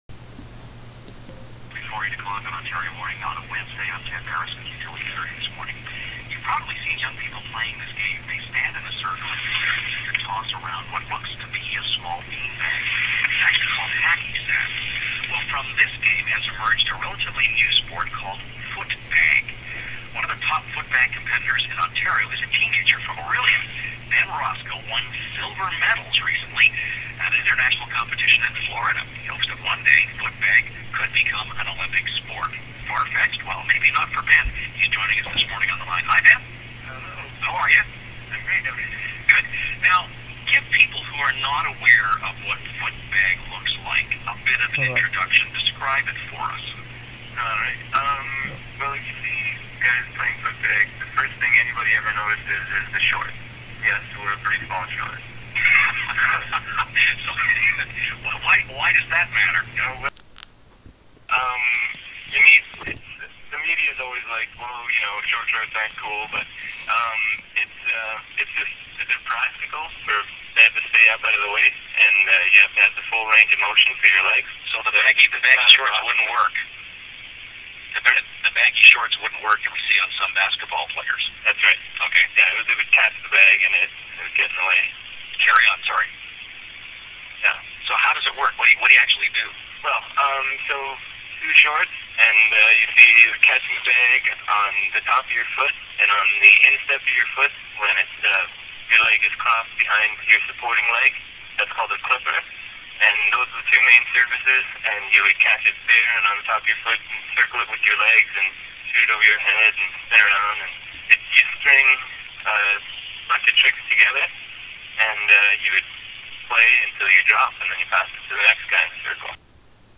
CBC Interview (Footbag).wma